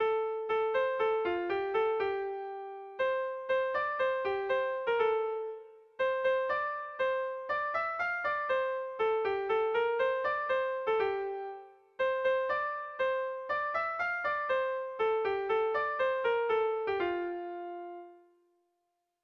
ABDEDE